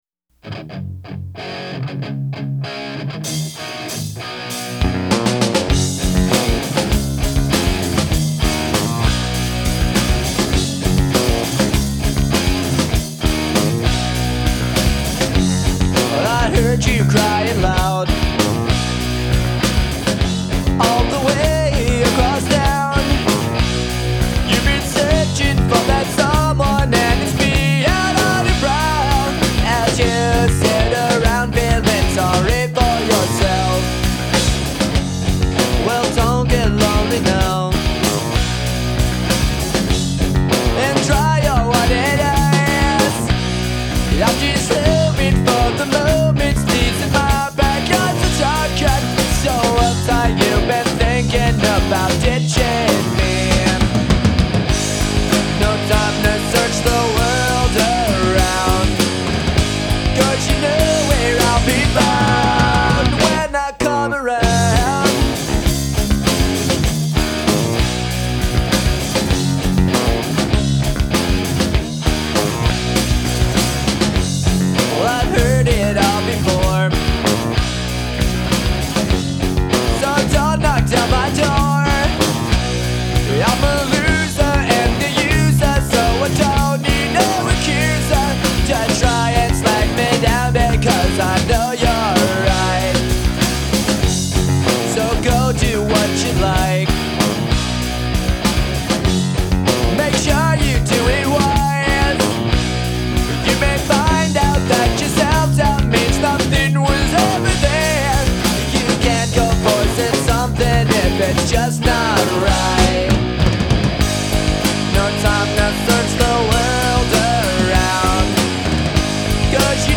Genre : Alternative & Indie
Cassette demo